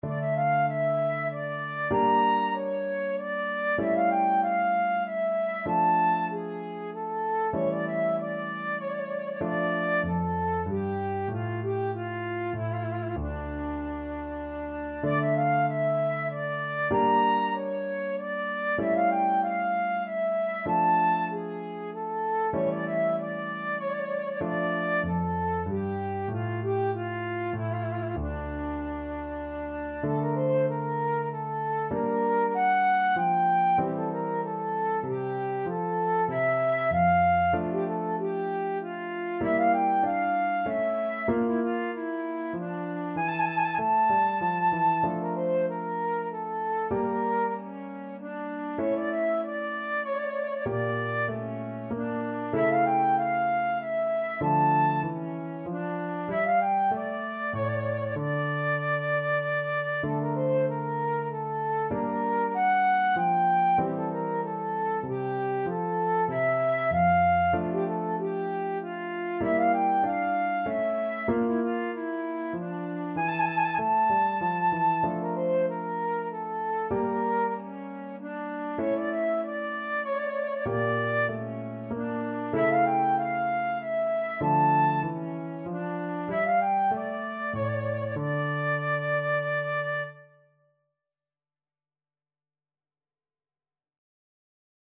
Classical Scarlatti, Domenico Sonata K.23 Flute version
Flute
3/8 (View more 3/8 Music)
Db5-Bb6
D minor (Sounding Pitch) (View more D minor Music for Flute )